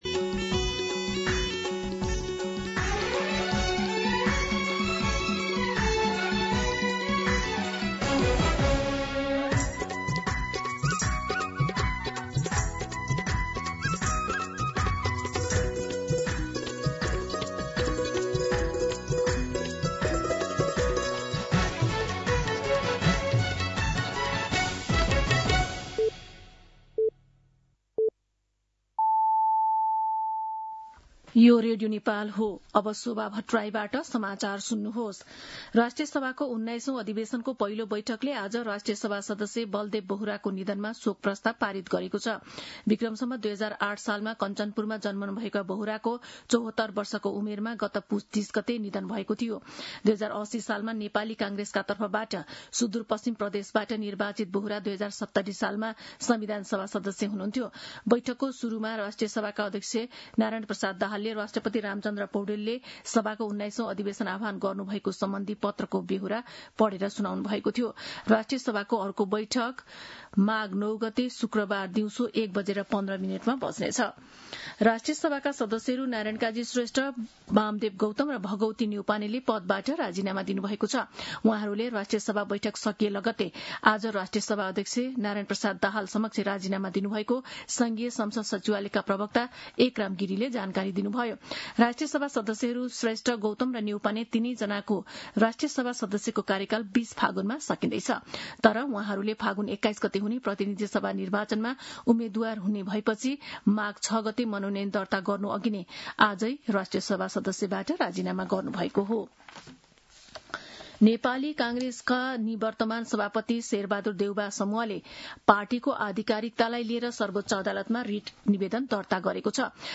दिउँसो ४ बजेको नेपाली समाचार : ४ माघ , २०८२
4pm-News-10-4.mp3